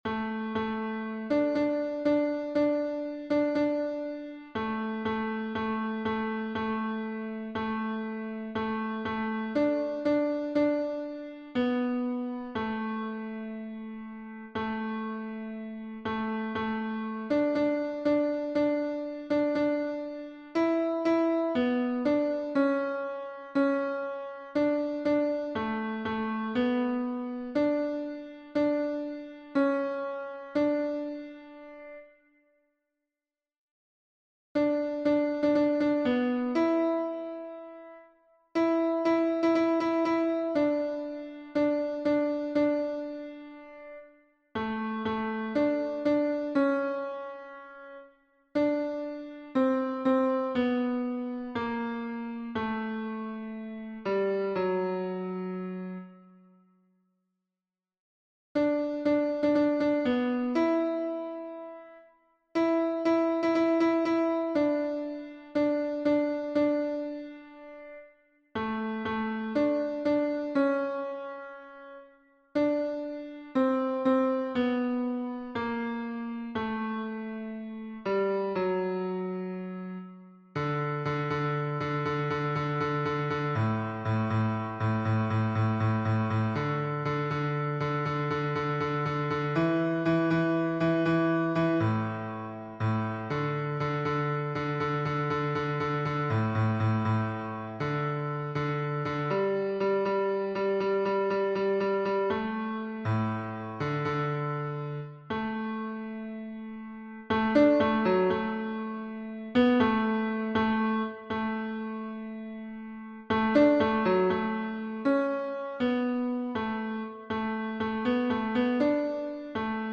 How Excellent Is Thy Loving Kindness — Tenor Audio.
How_Excellent_Is_Thy_Loving_Kindness_tenor.mp3